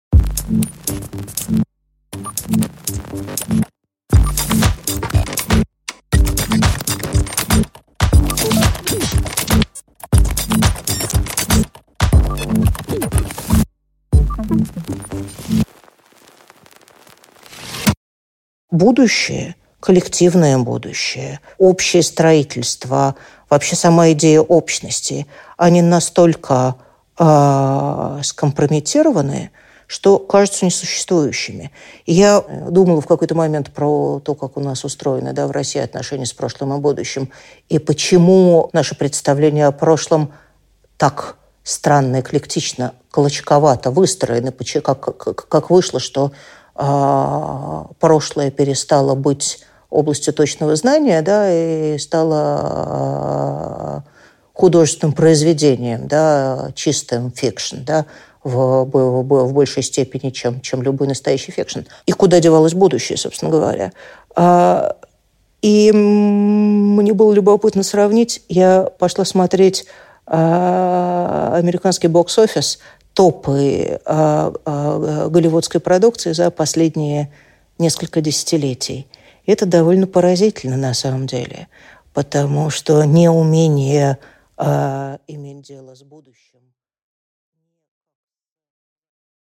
Аудиокнига Образы прошлого и будущего в постсоветскую эпоху | Библиотека аудиокниг
Прослушать и бесплатно скачать фрагмент аудиокниги